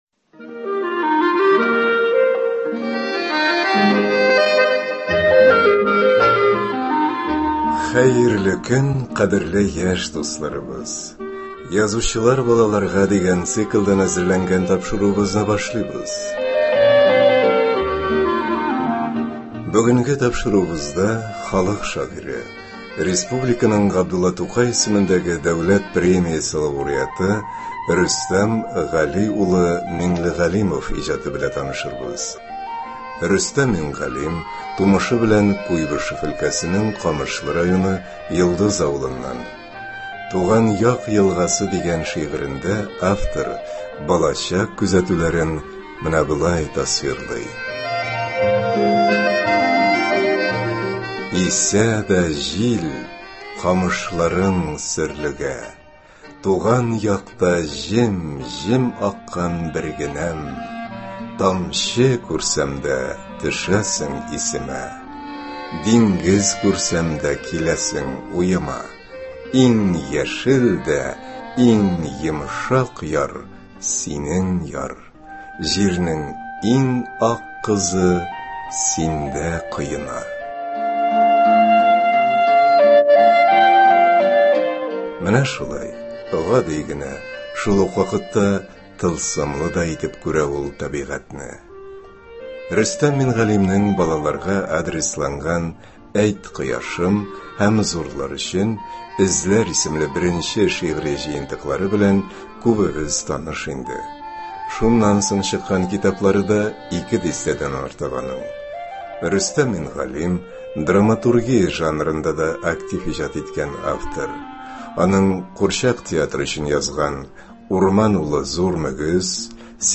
Тапшыруда шулай ук шагыйрьнең үз язмасында кайбер шигырьләрен дә ишетерсез.